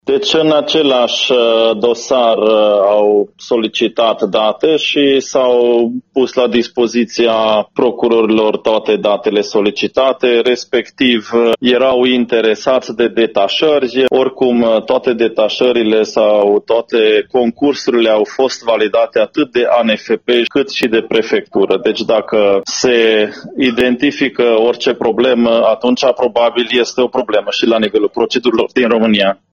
Procurorii DNA au fost preocupați, în prima speță, de detașările de personal, a precizat pentru Radio Târgu Mureș primarul municipiului, Soós Zoltán: